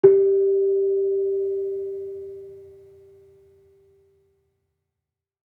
Gamelan Sound Bank
Kenong-resonant-G3-f.wav